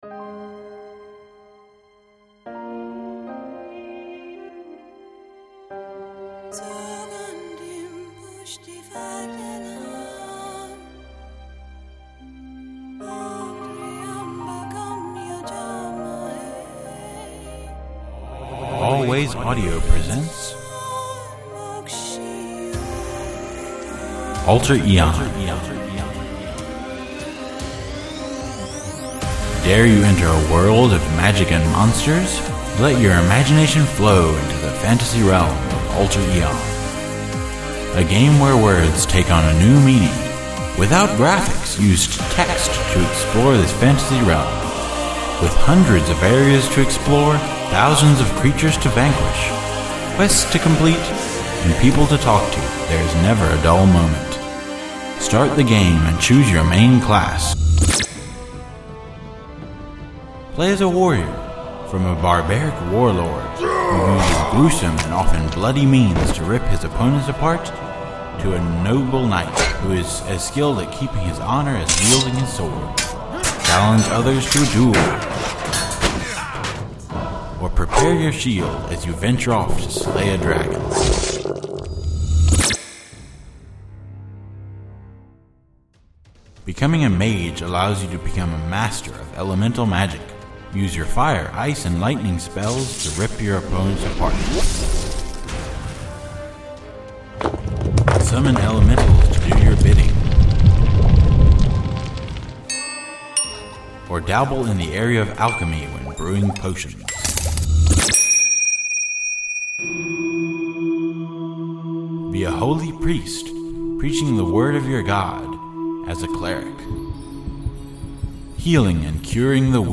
The World of Alter Aeon (Promotion Advert)